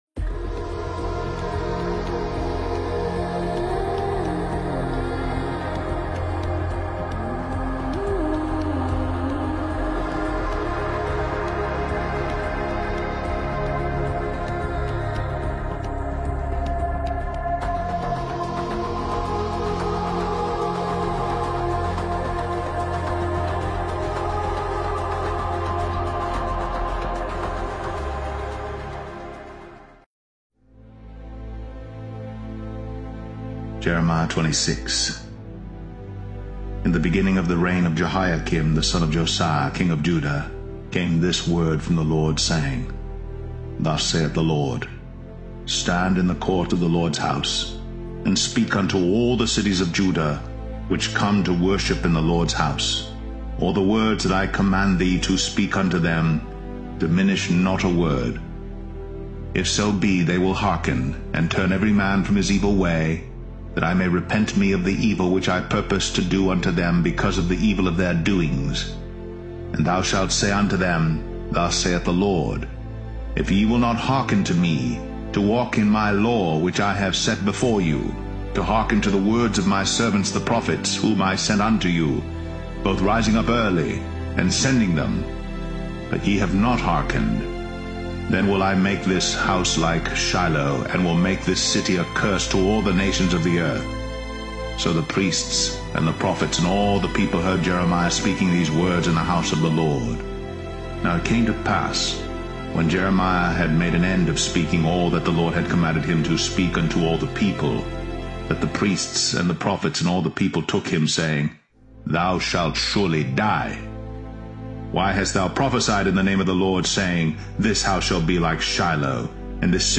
[Apr 13, 2023] CuttingEdge: Jeremiah 26 Reading